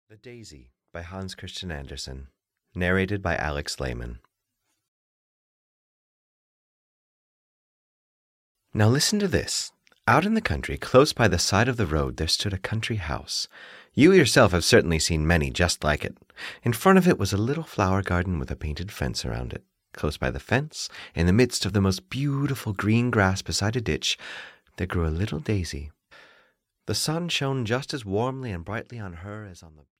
The Daisy (EN) audiokniha
Ukázka z knihy